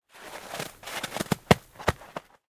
snowball.ogg